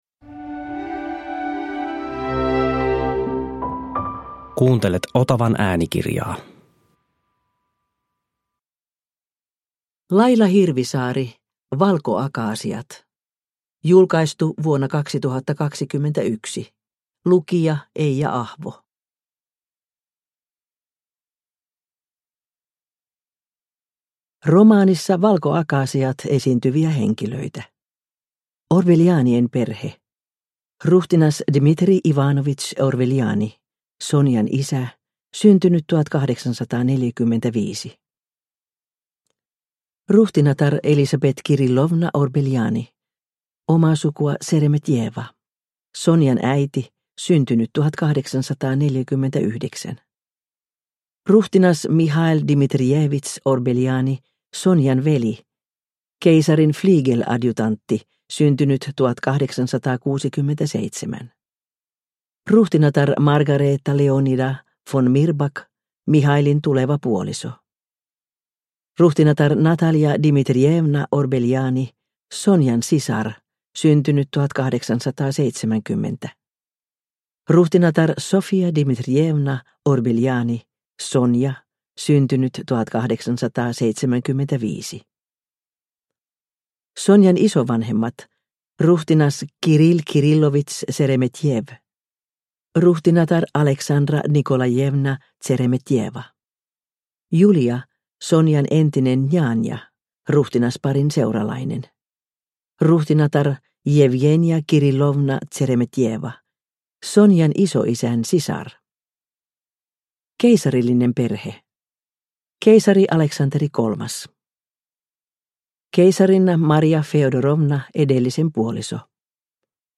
Valkoakaasiat (ljudbok) av Laila Hirvisaari